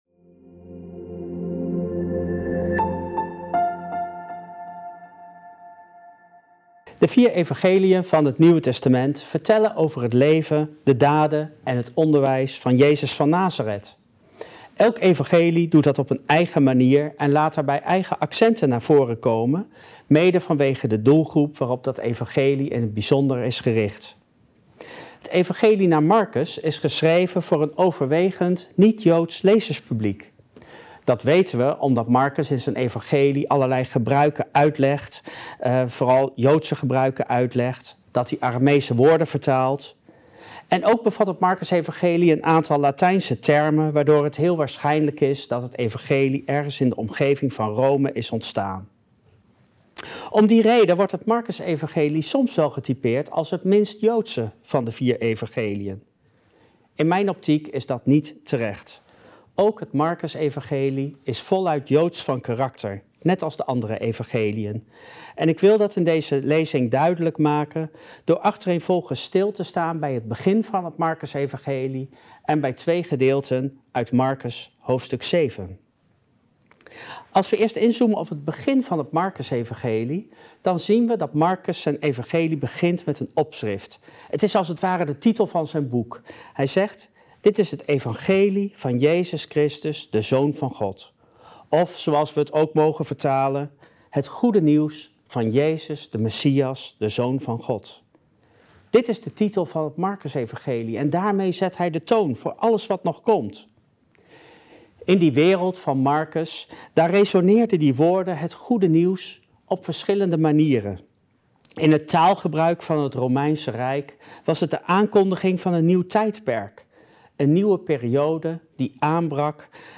Israël in het hart van het Evangelie van Marcus • Bijbelstudie